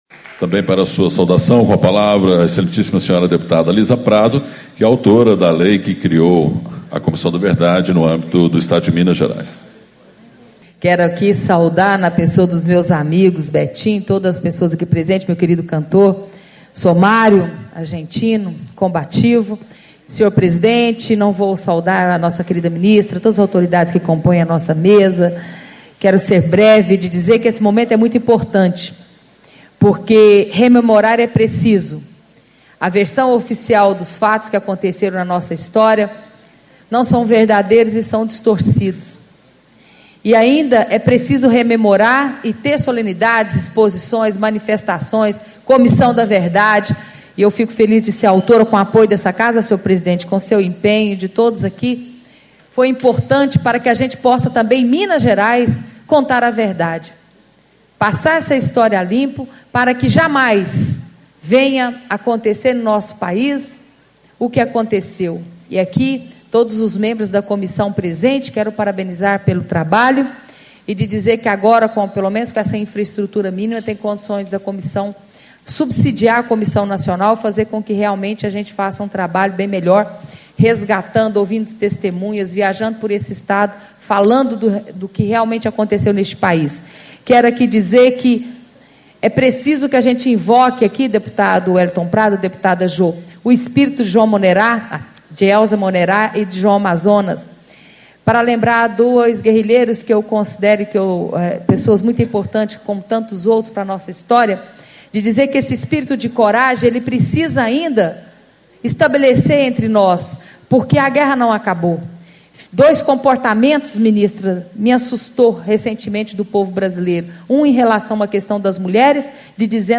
Abertura - Deputada Liza Prado, PROS
Discursos e Palestras